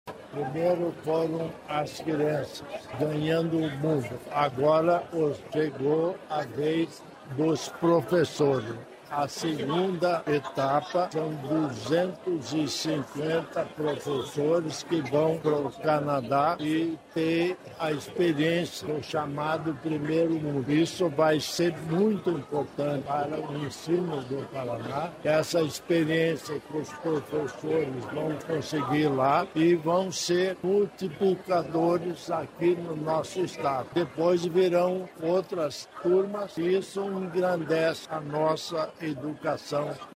Sonora do vice-governador, Darci Piana, sobre o intercâmbio dos professores no Canadá